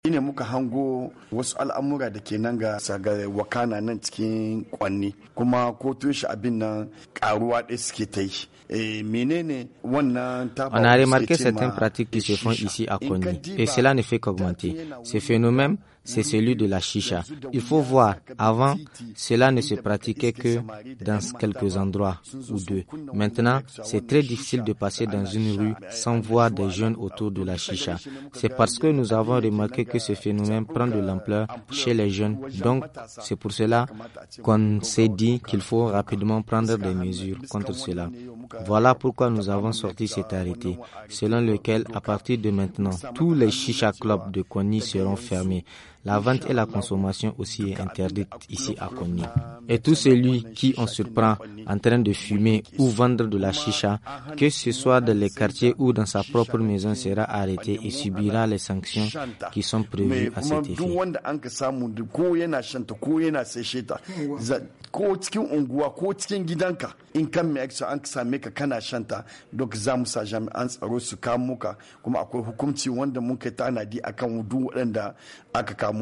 Interview de Soumaila Ousmane, maire de la commune urbaine Konni